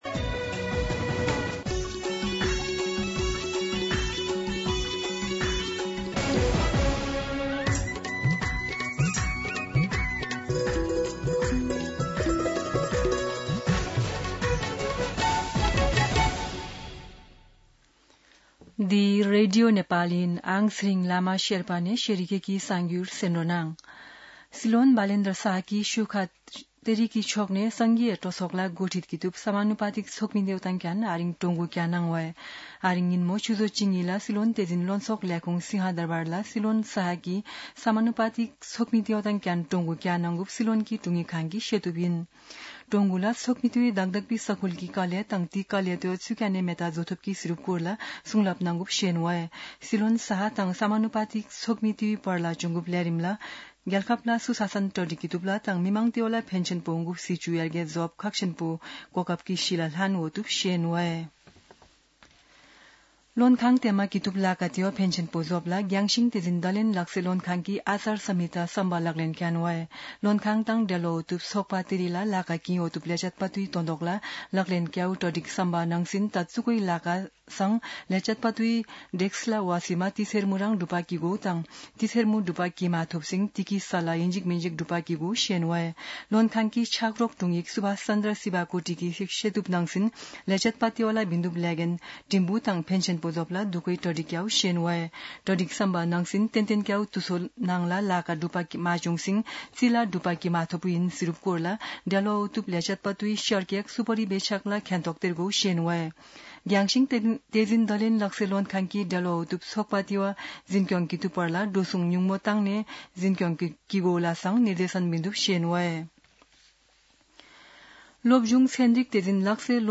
शेर्पा भाषाको समाचार : २ वैशाख , २०८३
Sherpa-News-1-2.mp3